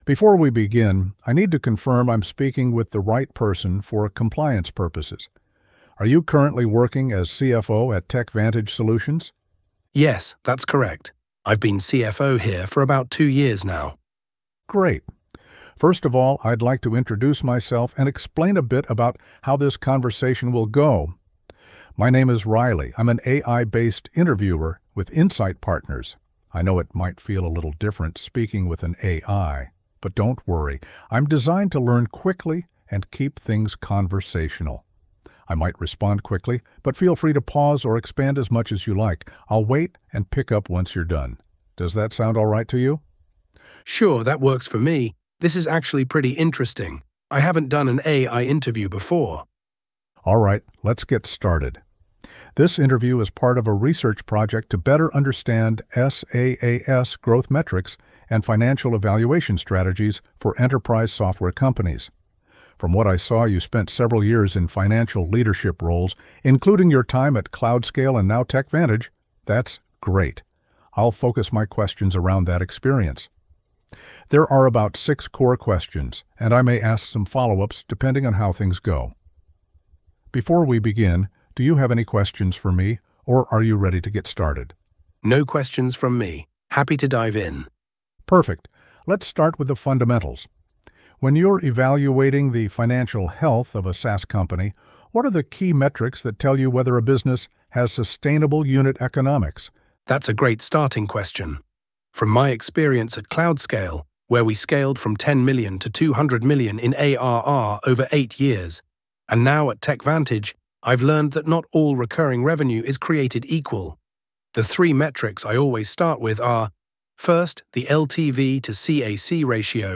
AI-moderated expert interviews with structured output.